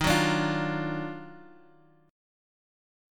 EbmM9 Chord
Listen to EbmM9 strummed